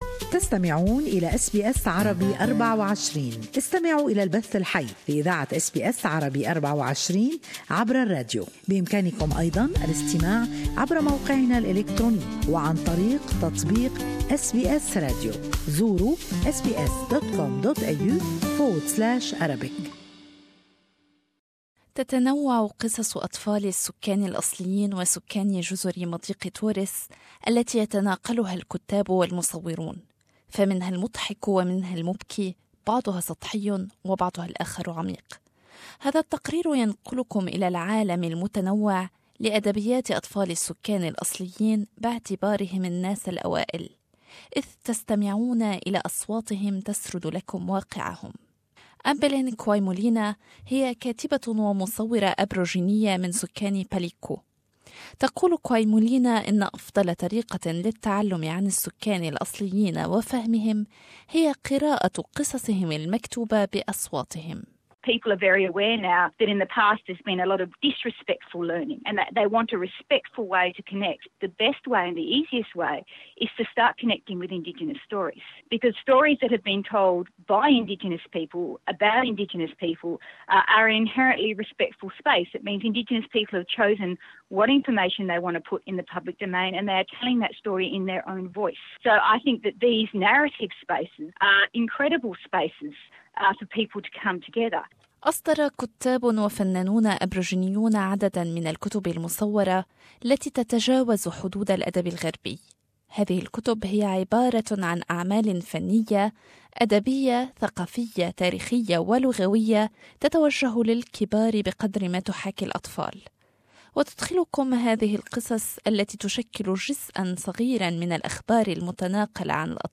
هذا التقرير ينقلكم إلى العالم المتنوع لأدبيات أطفال السكان الأصليين باعتبارهم الناس الأوائل إذ تستمعون إلى أصواتهم تسرد لكم واقعهم.